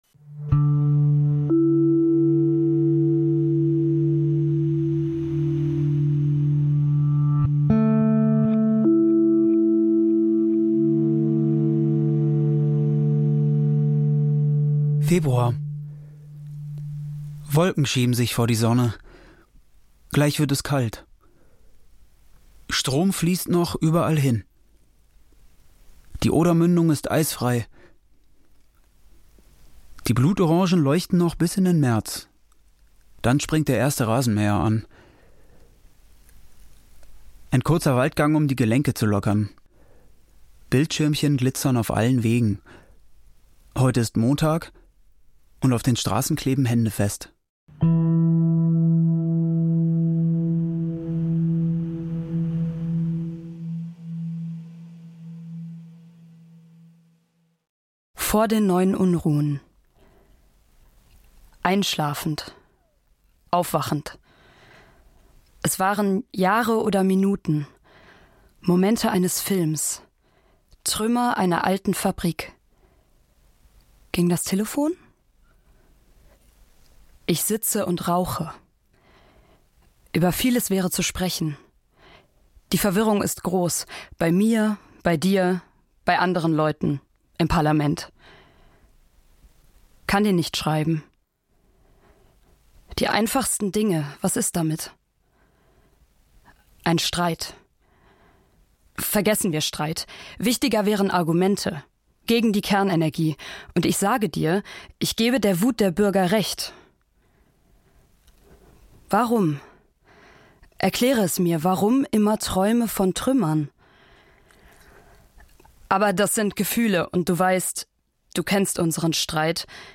Gedichte